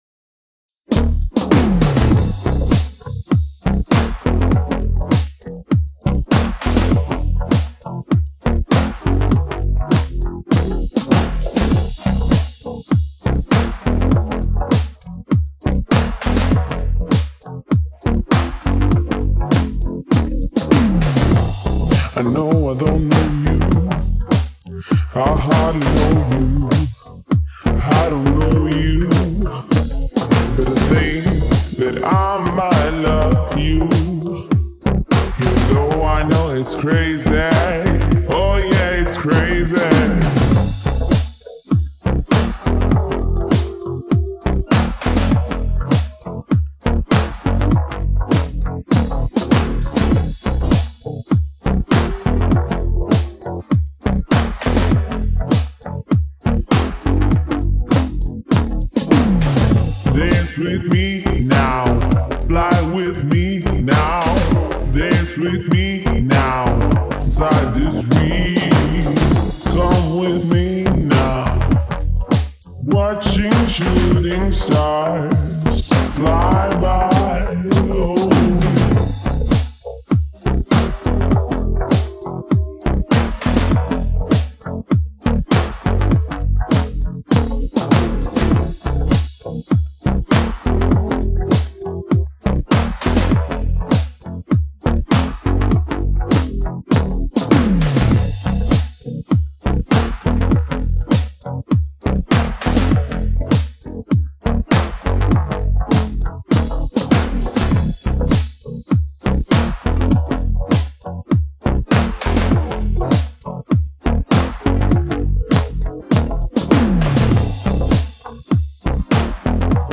Synthetic noodles |pop|
Парни, накидайте причин, пожалуйста, почему при mixdown'е обе партии баса отъехали ритмически?
P.S. На основном басу Knif Audio Knifonium - он "тяжёлый".